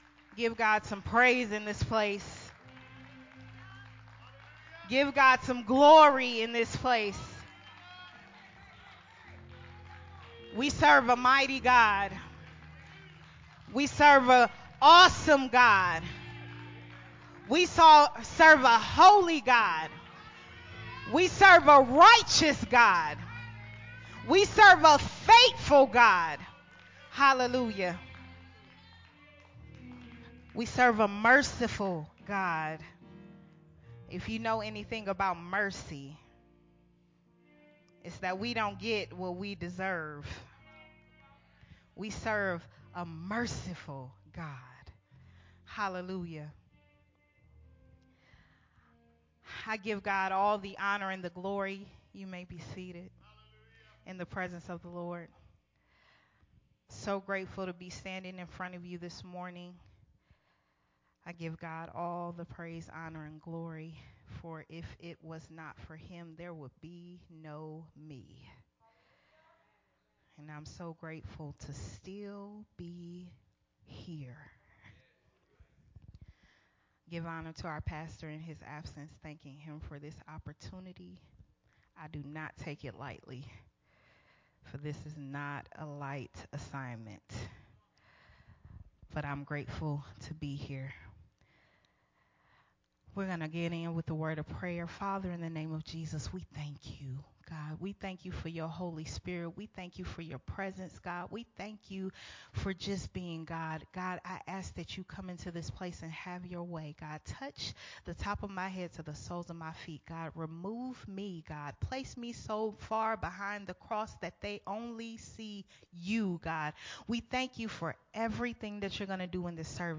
a Sunday Morning message
recorded at Unity Worship Center on July 10